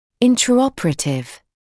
[ˌɪntrə’ɔpərətɪv][ˌинтрэ’опэрэтив]происходящий во время операции, интраоперационный